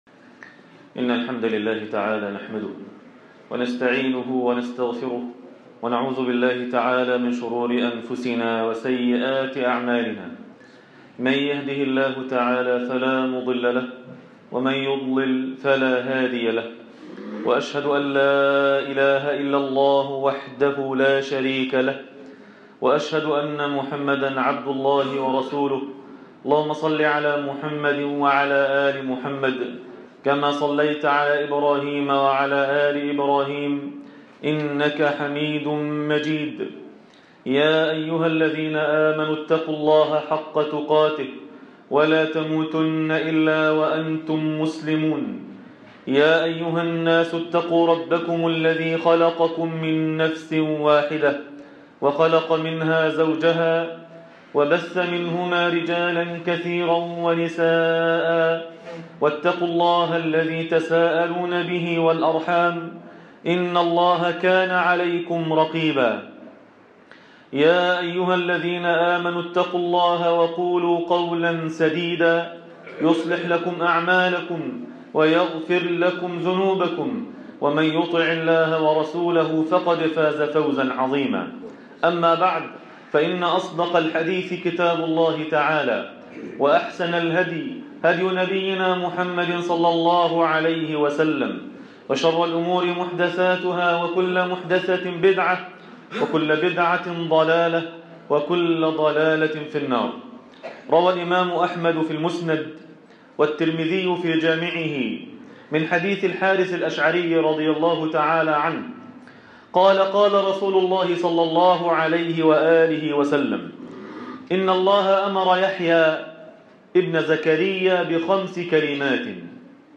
كلمات من يحيي عليه السلام | خطبة جمعة